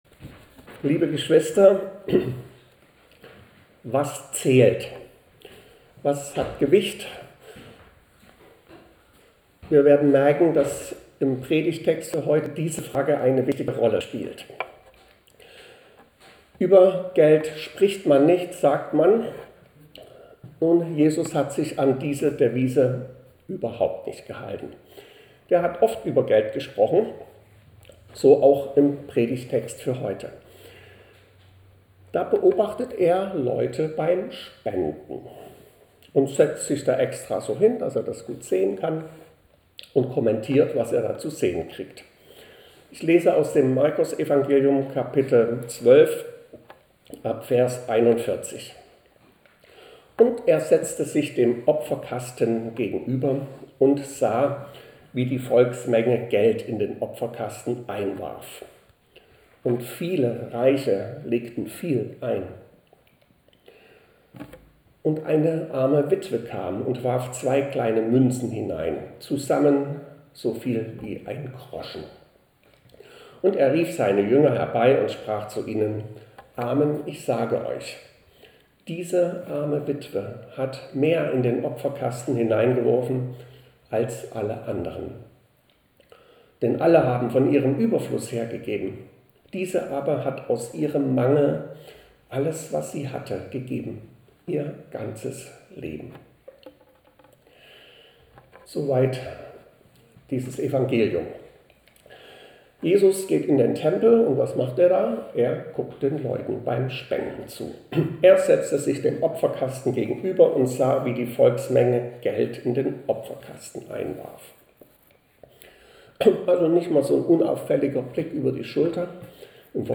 Predigten - Evangelisch-Freikirchliche Gemeinde Berlin Pankow (Niederschönhausen)
Die Tonqualität ist diesesmal leider schlechter, weil die Predigt nur mit dem Handy aufgenommen wurde.